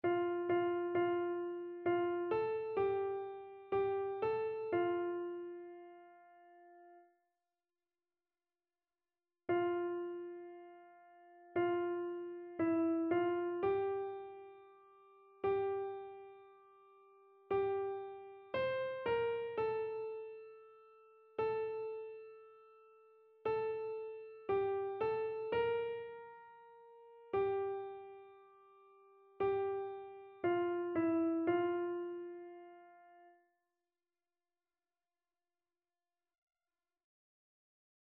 Chœur
Soprano
annee-c-temps-ordinaire-7e-dimanche-psaume-102-soprano.mp3